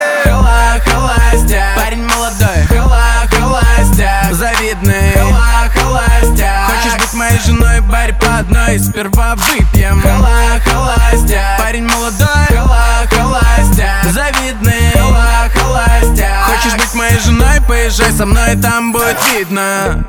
• Качество: 320, Stereo
Хип-хоп
веселые